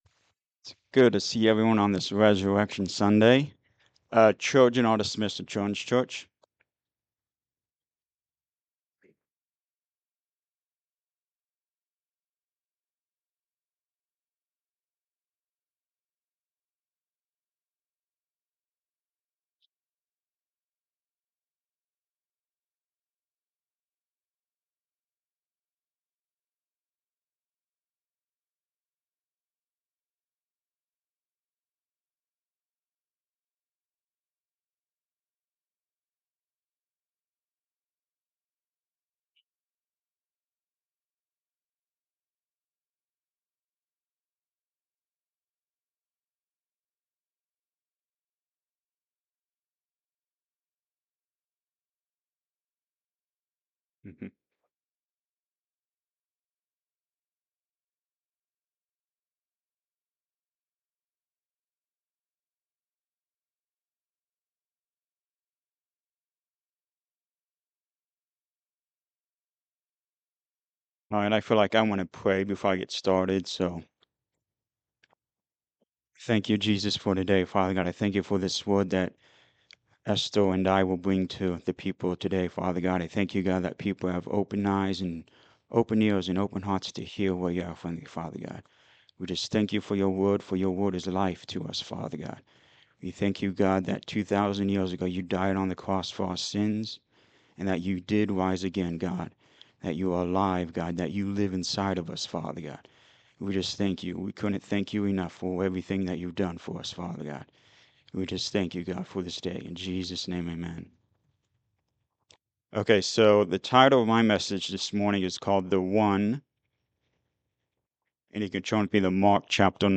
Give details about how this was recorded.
Mark 5 Service Type: Sunday Morning Service The Resurrection of Jesus Christ is the most amazing part of our existence.